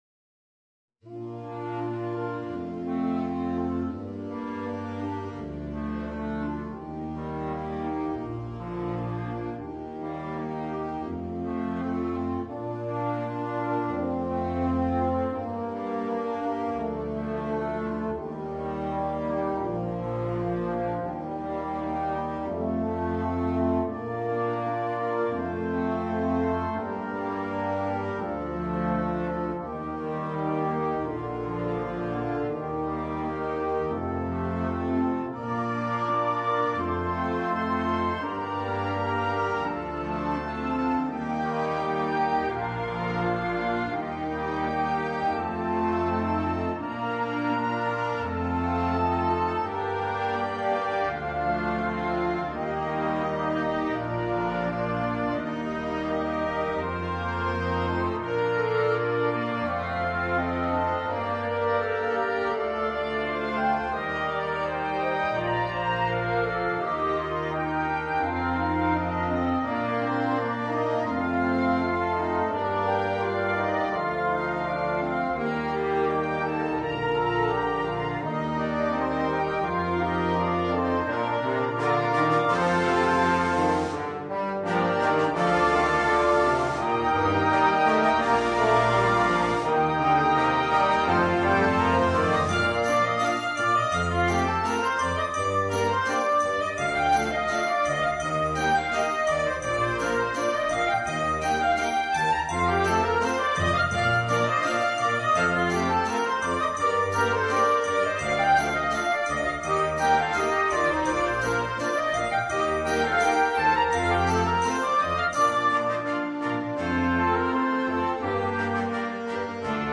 Per banda